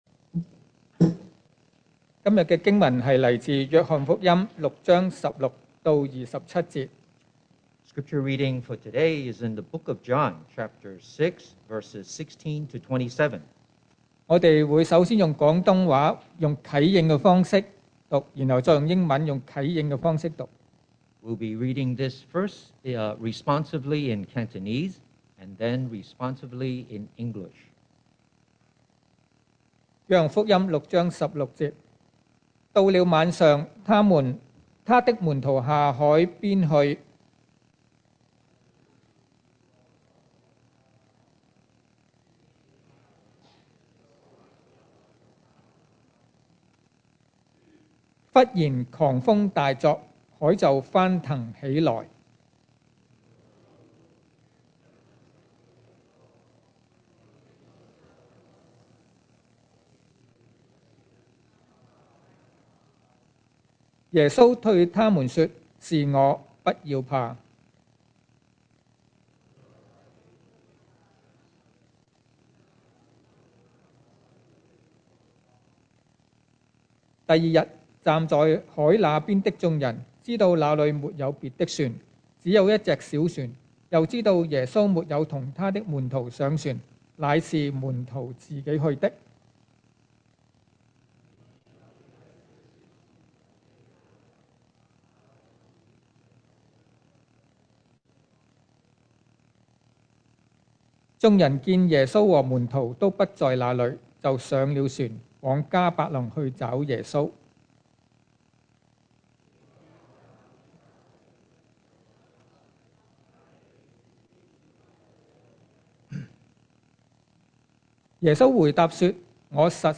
2023 sermon audios 2023年講道重溫 Passage: Joshua 6:16-27 Service Type: Sunday Morning Who Is Jesus?